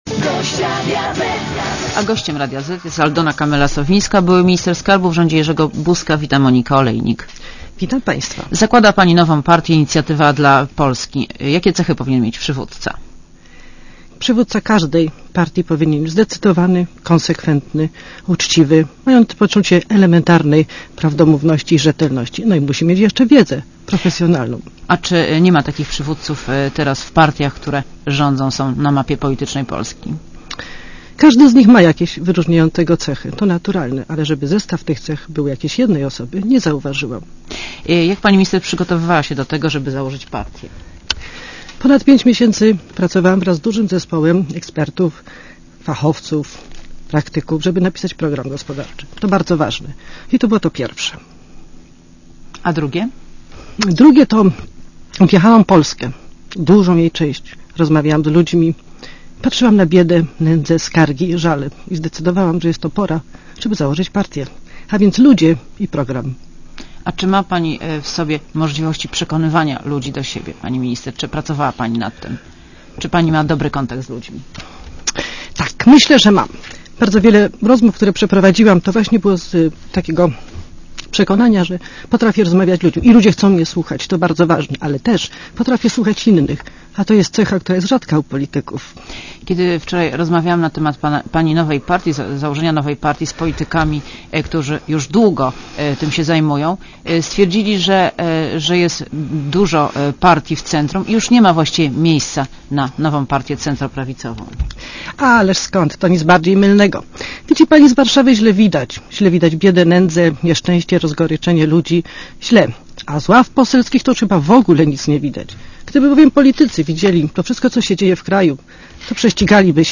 Monika Olejnik rozmawia z Aldoną Kamelą-Sowińską - minister skarbu w rządzie Jerzego Buzka